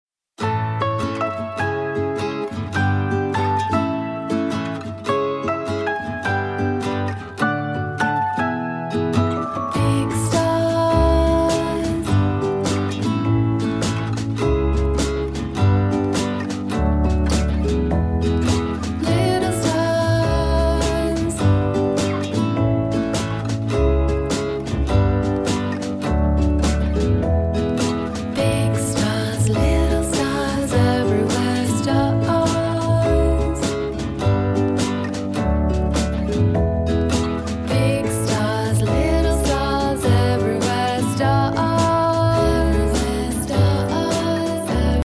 mindful and quietly exultant music